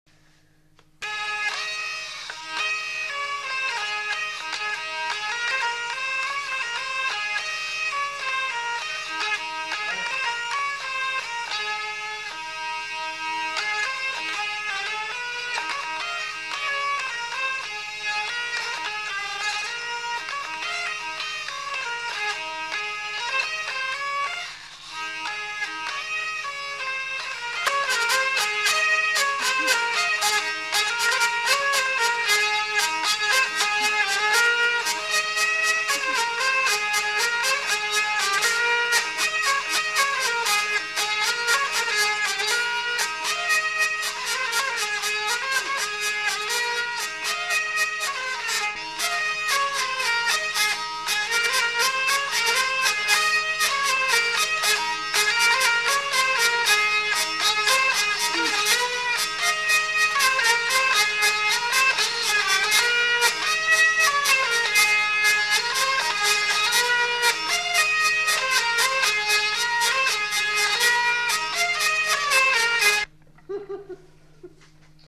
Aire culturelle : Gabardan
Lieu : Vielle-Soubiran
Genre : morceau instrumental
Instrument de musique : vielle à roue
Danse : rondeau